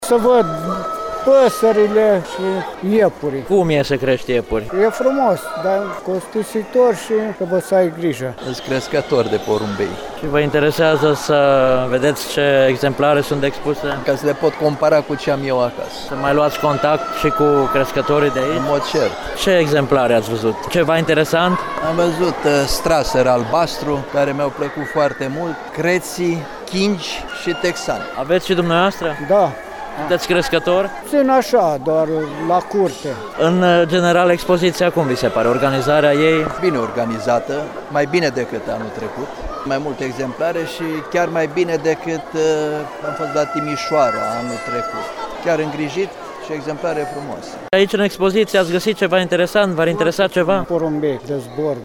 De la câţiva dintre vizitatorii prezenţi aflăm pentru ce au venit la eveniment: